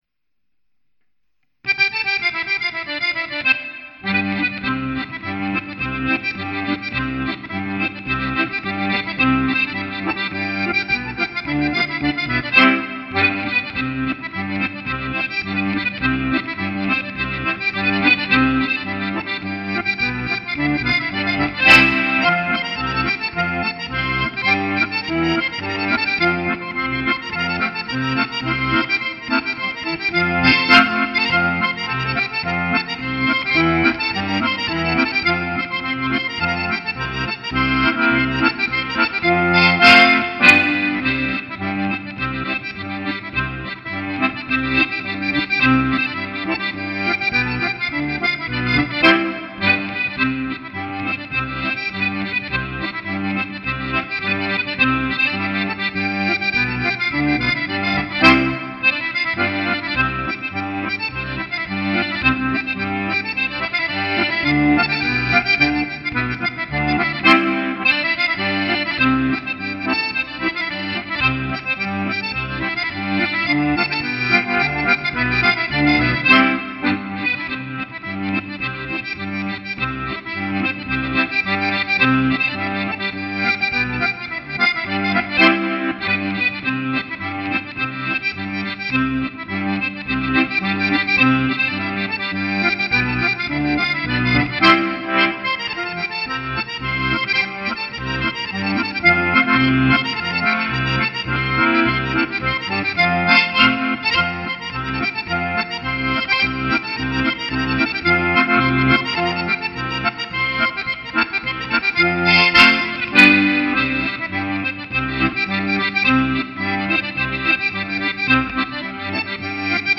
SCOTTIS a casa con il mio pc, ho usato la mia fisarmonica senza ausilio di nessun tipo di elettronica, praticamente live al 100%.
scottisreverb.mp3